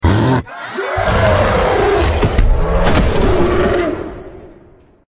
Massive Gorilla Fights Grizzly Bear#shorts sound effects free download